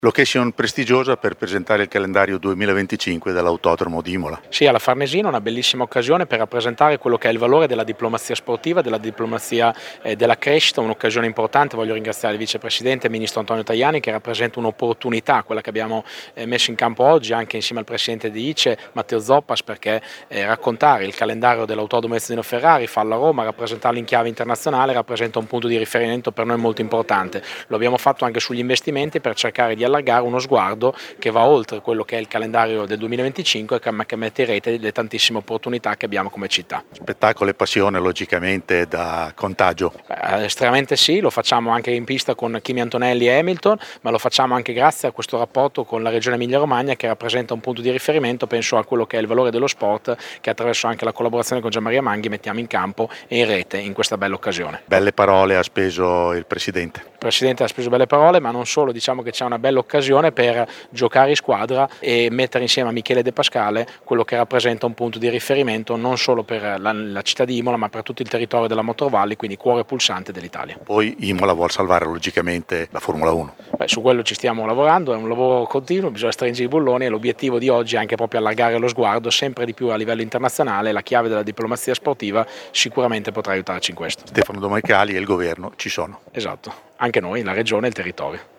Marco Panieri, sindaco di Imola, al microfono